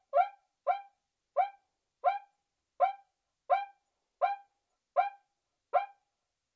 Cuica " cuica (12)
描述：桑巴batucada乐器的不同例子，发出典型的sqeaking声音。马兰士PMD671，OKM双耳或Vivanco EM35.
Tag: 巴西 图案 打击乐器 节奏 桑巴